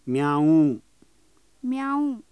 猫 (cat) billii (WAV 18K)ニャー (mew, meow, miaow)myAAUU